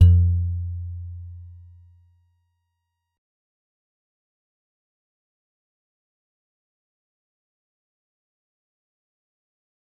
G_Musicbox-F2-mf.wav